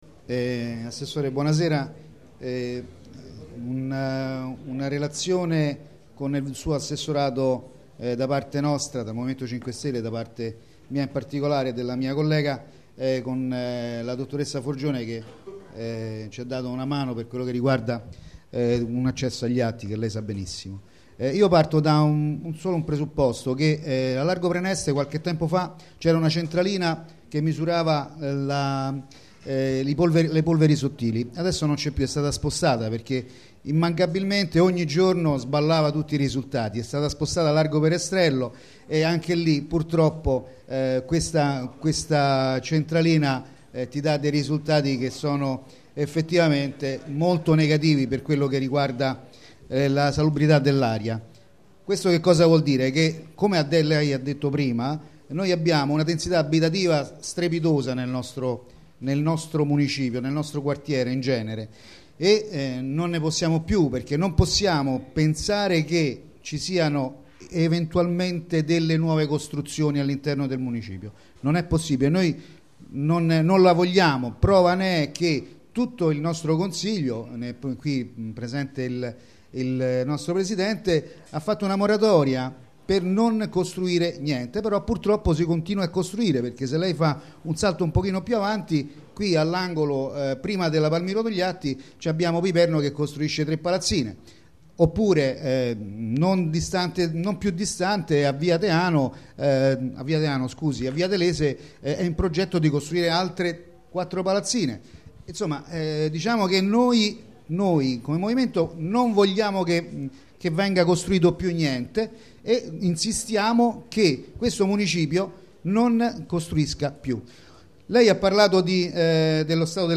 Giovanni Boccuzzi, consigliere M5S Municipio V